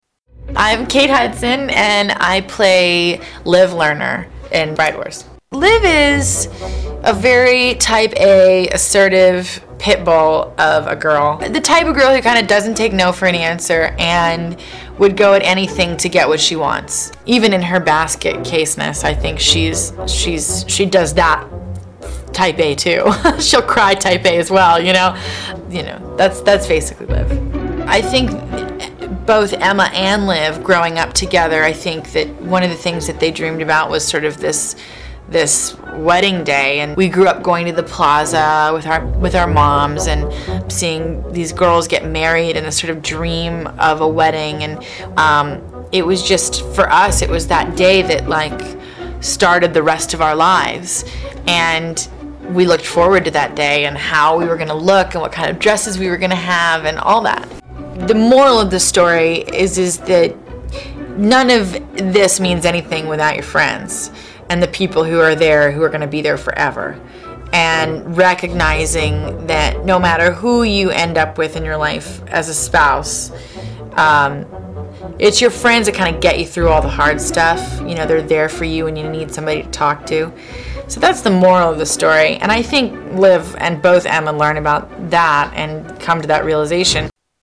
Kate Hudson Interview